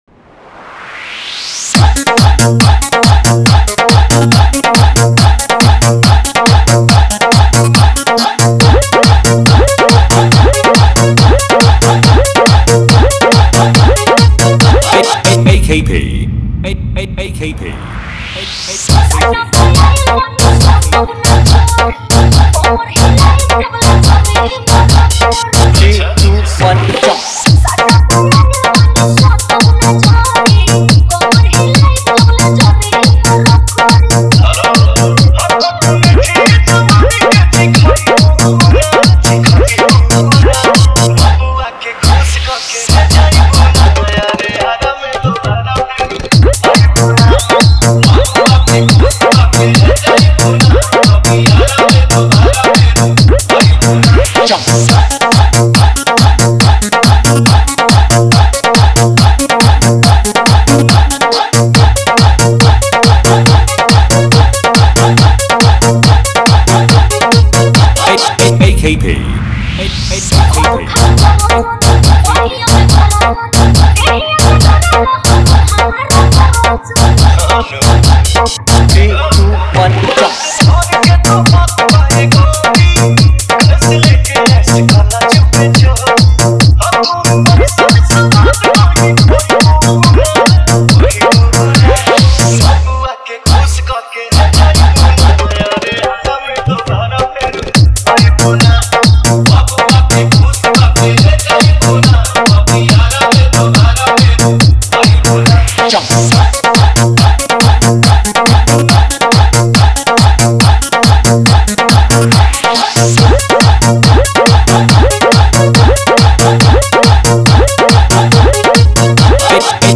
Category: Holi Dj Songs 2022